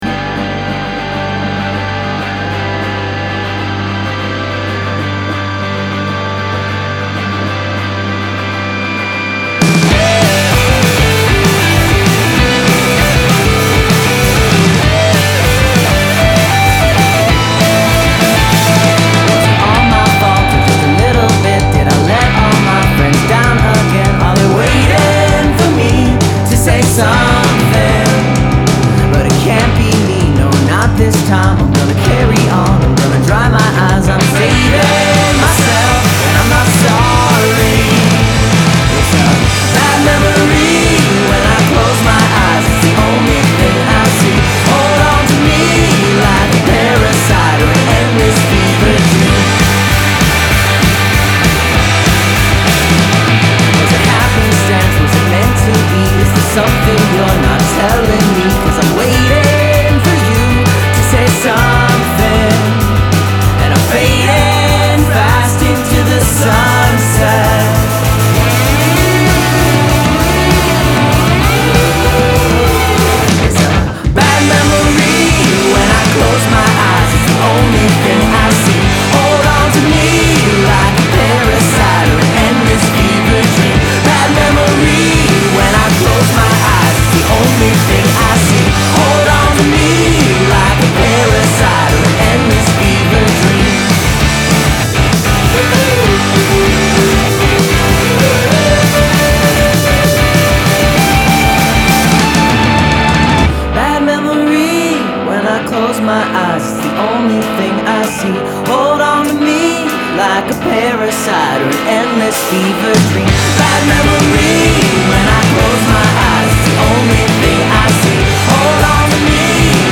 Additional percussion